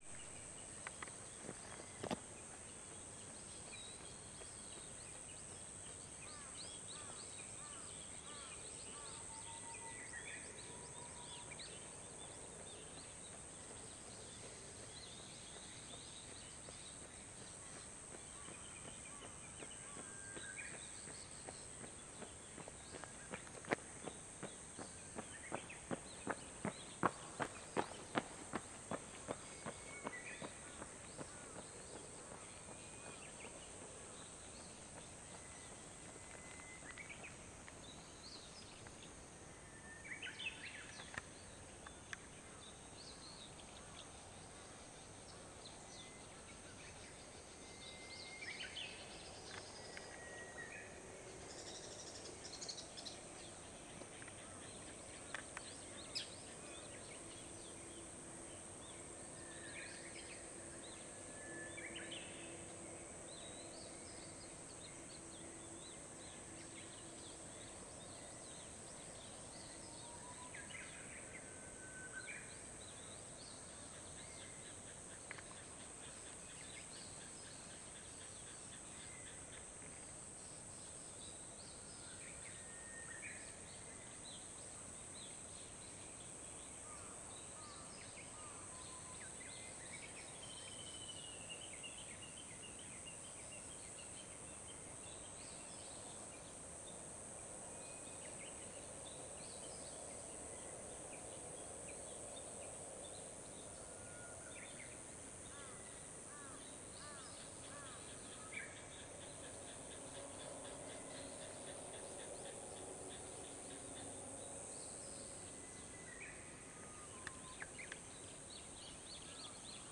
Here is a field recording from a few years back.
The sounds were recorded in Narita, Japan around 5 AM. The planes had not invaded the audiovisual space yet, so there is  a feeling of tranquility. There were a few people walking and exercising near the bamboo forest, but for the most part I was alone in this landscape.